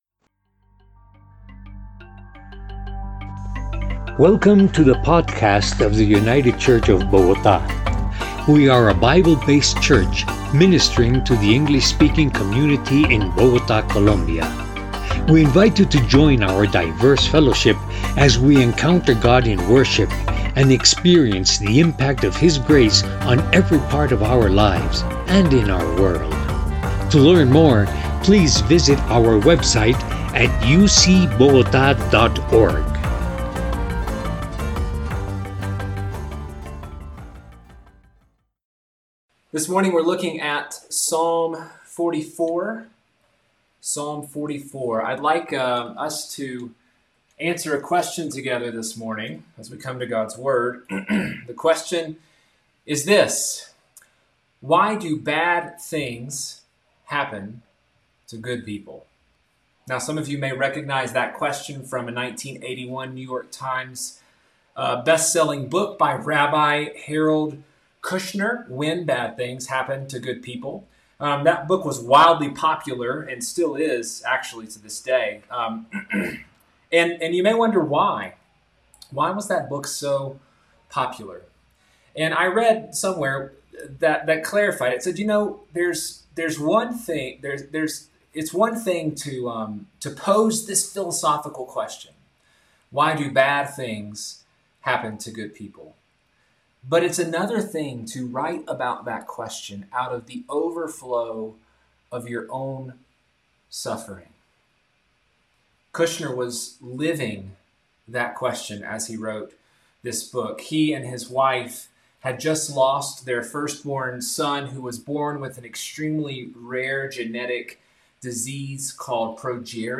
By United Church of Bogotá | 2020-07-19T16:30:24-05:00 June 28th, 2020 | Categories: Sermons | Tags: Summer Psalms | Comments Off on Why Do Bad Things Happen to Good People?